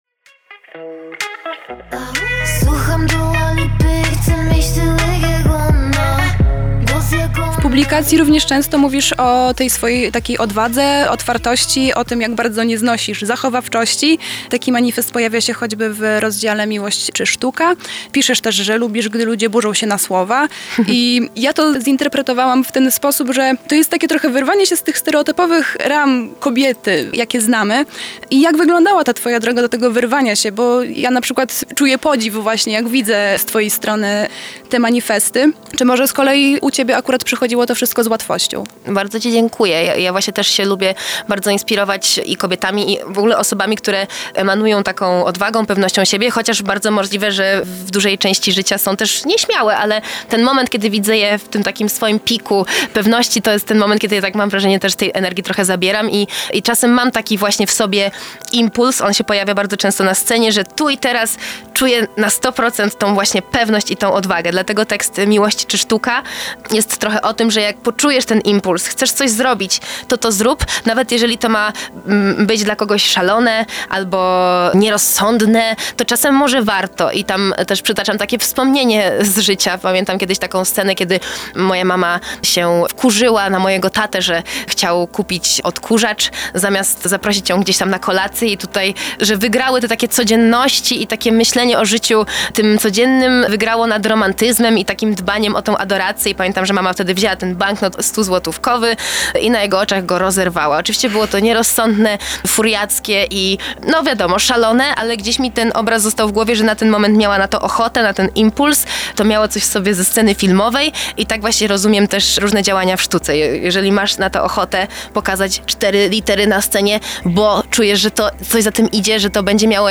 mery-wywiad2.mp3